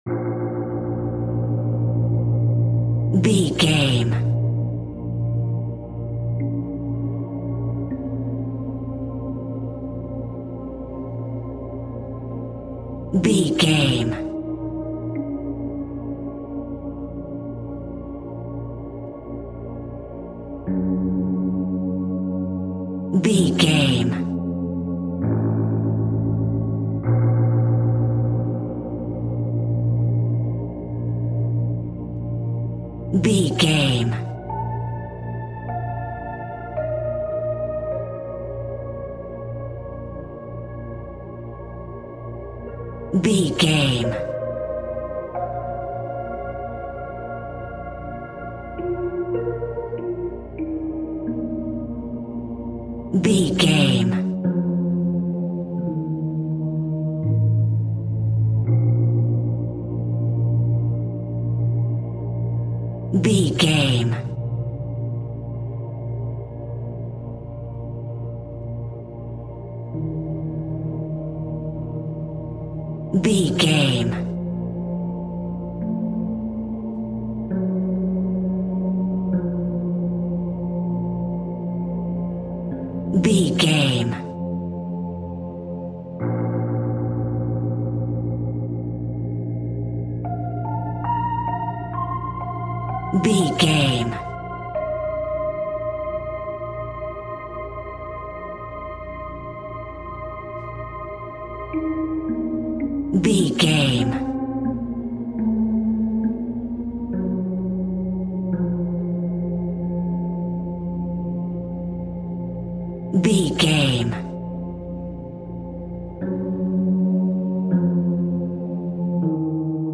Aeolian/Minor
B♭
scary
ominous
dark
suspense
haunting
eerie
synthesizer
horror
ambience
pads
eletronic